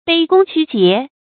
卑躬屈節 注音： ㄅㄟ ㄍㄨㄙ ㄑㄩ ㄐㄧㄝ ˊ 讀音讀法： 意思解釋： 卑躬：低頭彎腰；屈節：屈辱節操。